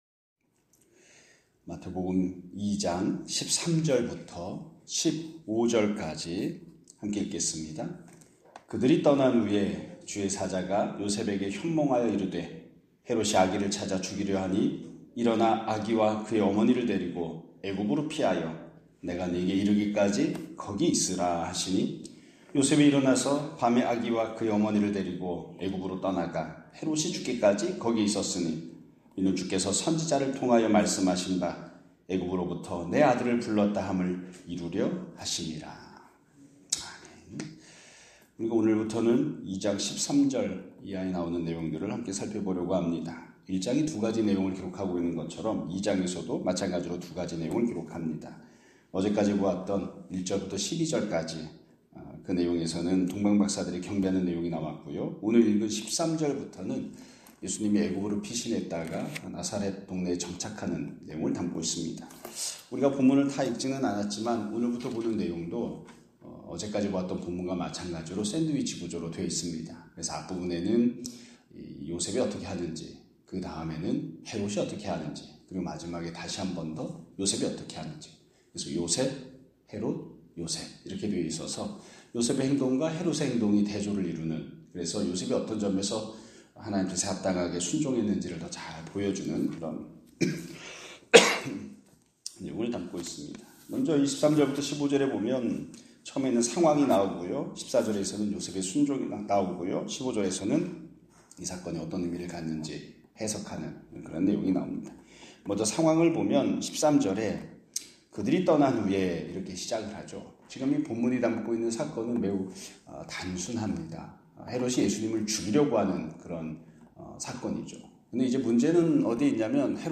2025년 4월 4일(금 요일) <아침예배> 설교입니다.